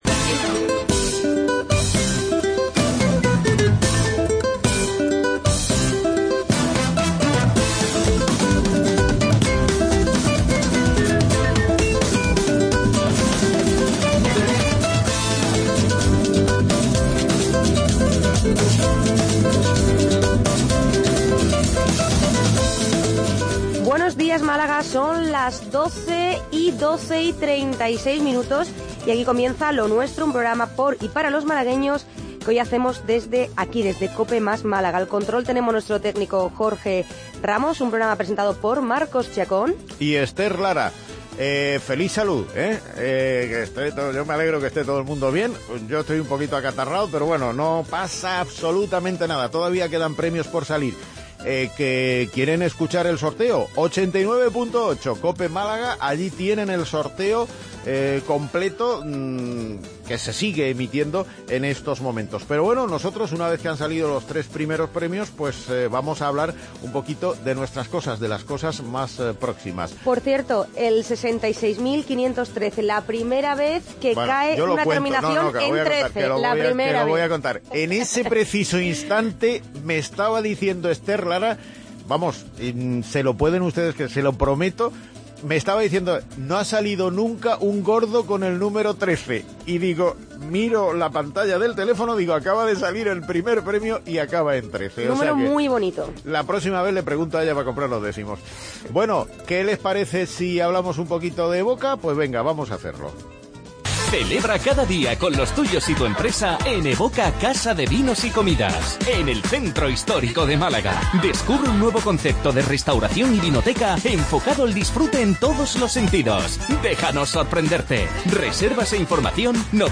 AUDIO: Magazine diario